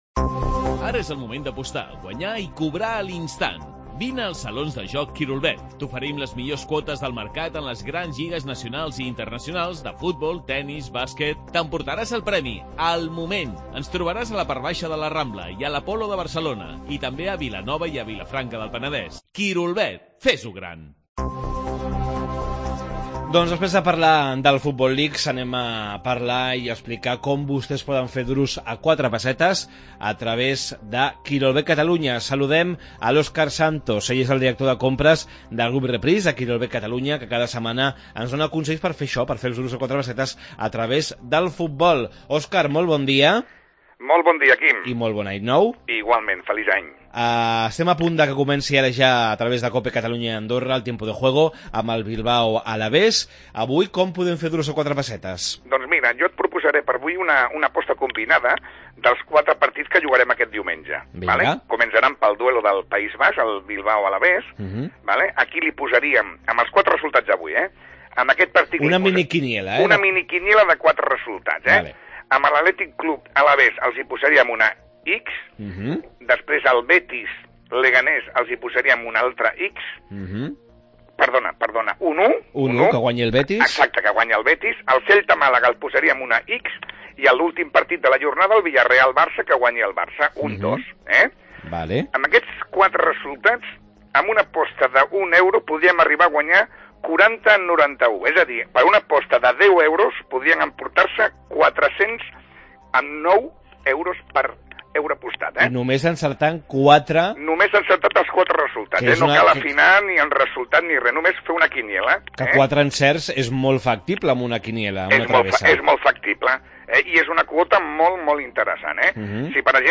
Com fer duros a quatre pessetes amb el futbol? Entrevista